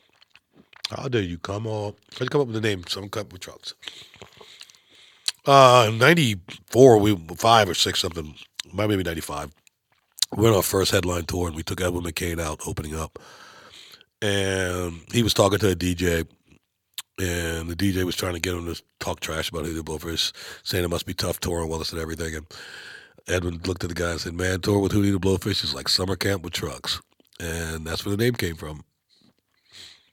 Darius Rucker explains how the name came about for this year's Hootie & The Blowfish Summer Camp With Trucks Tour.